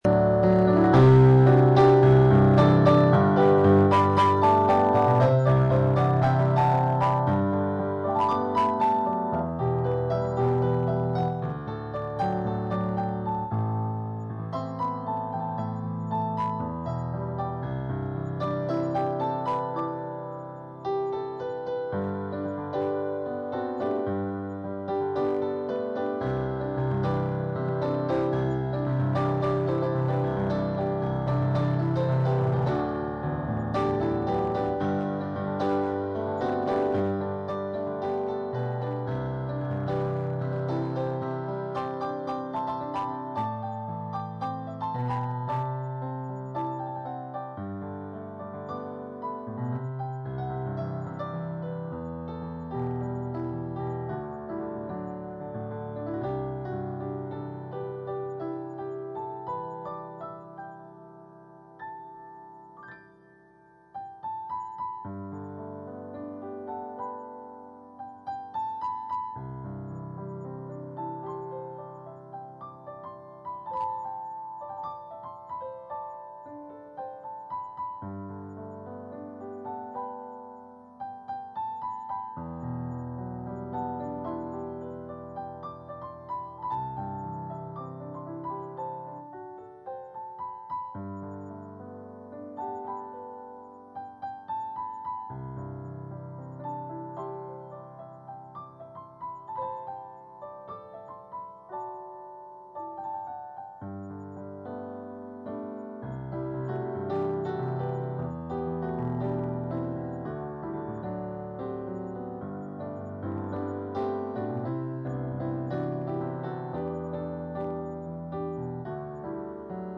SERVICE-God-So-Loved-the-World.mp3